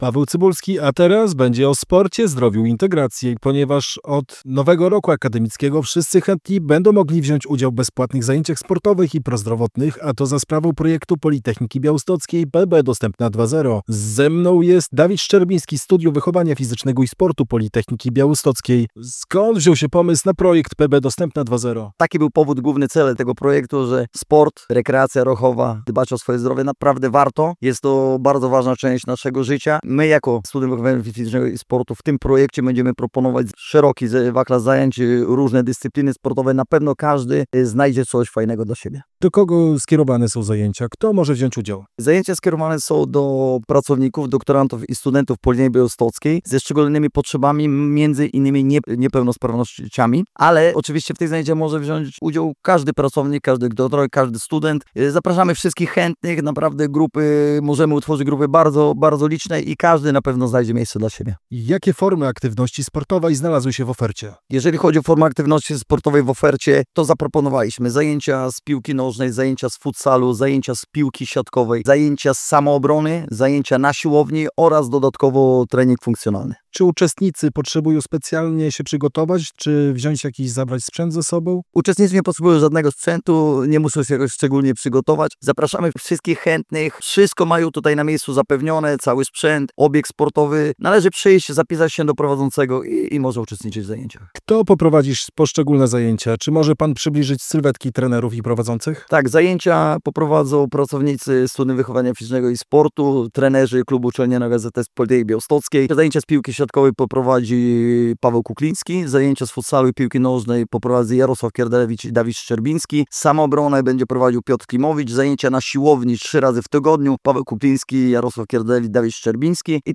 O zajęciach „PB Dostępna 2.0” opowiadał na antenie radia Akadera